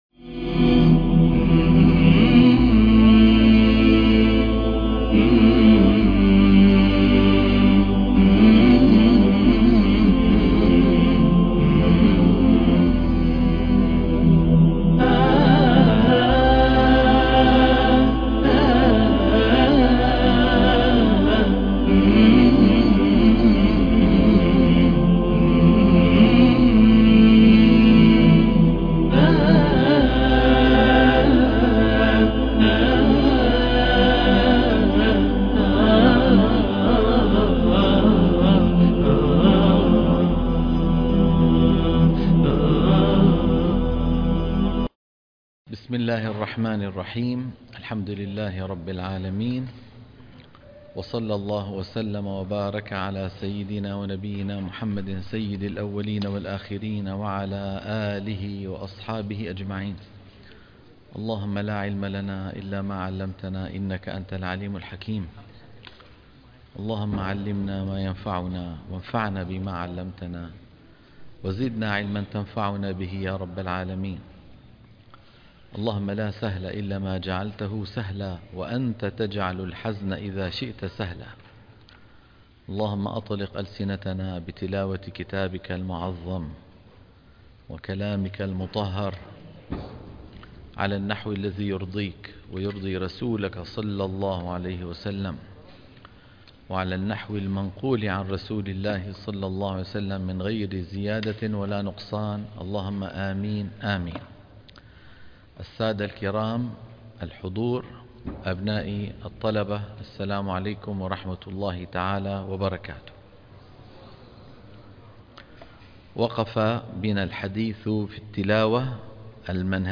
برنامج تصحيح التلاوة الحلقة - 95 - تصحيح التلاوة تلقين الصفحة 325 - الشيخ أيمن سويد